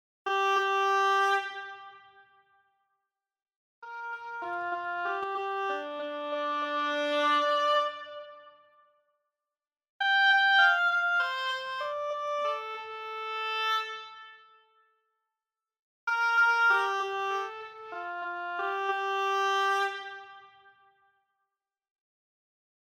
Die Oboe, Sforzando, mit zweifacher Repetition, animierter Dynamik, Tightness und Vibrato. Ich habe eine Mischung aus Close-, Mid- und Far-Mikrofonierung verwendet: